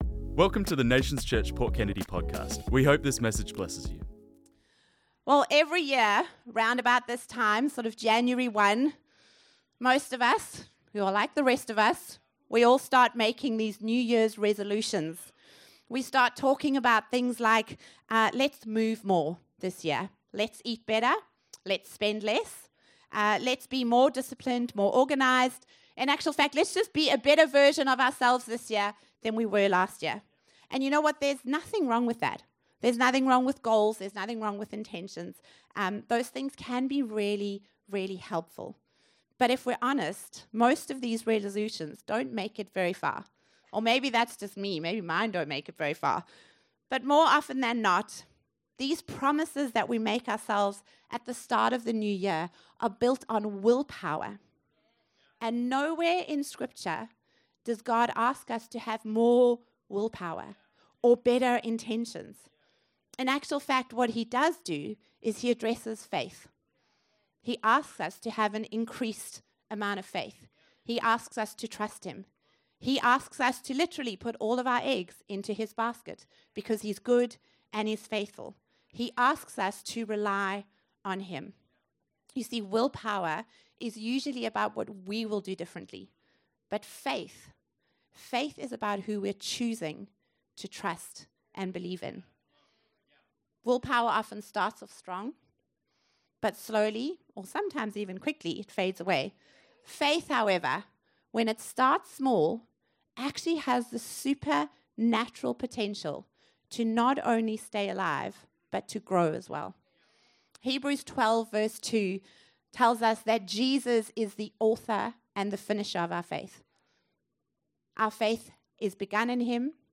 This message was preached on Sunday the 4th January 2026